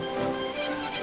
Added violin